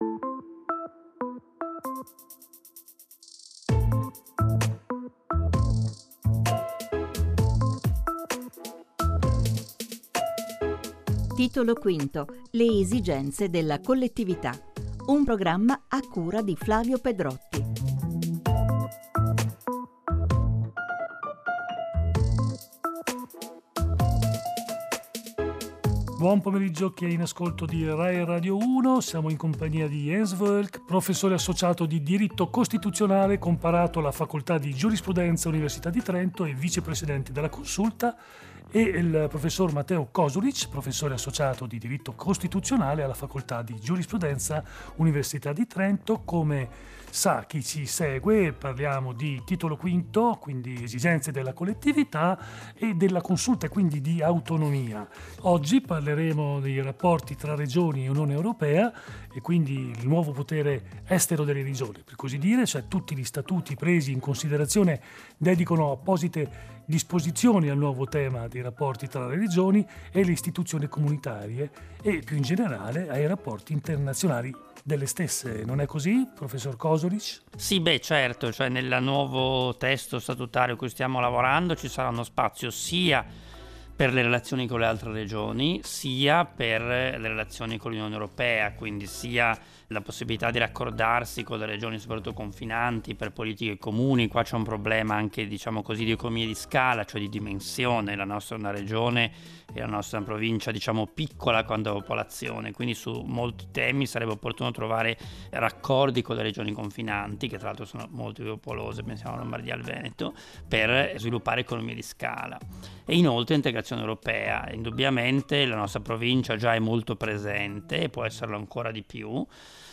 Rapporti con le altre regioni e integrazione europea / Notiziario radiofonico / News / RIFORMA DELLO STATUTO -
La puntata è stata trasmessa il 13 febbraio su Rai Radio Uno.